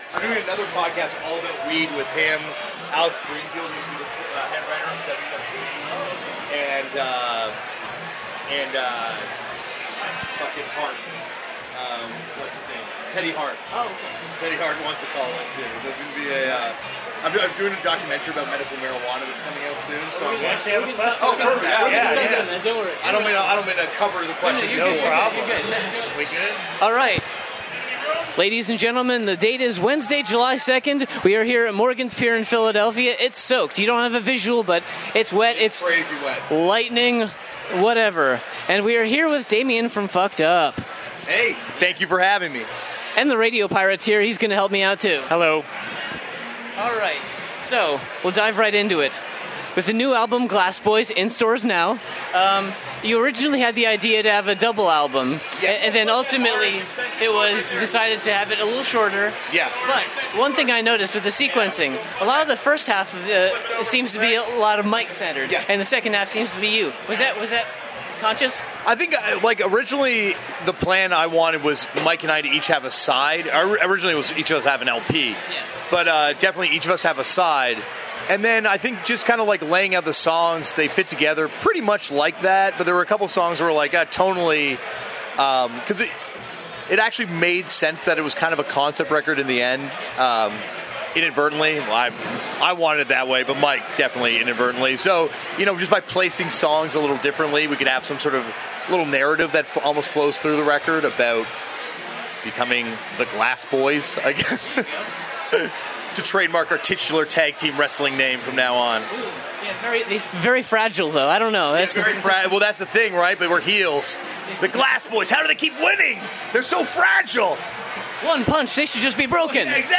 The interview took place during soundcheck right before the band went out on stage which explains any background noise.
47-interview-fucked-up.mp3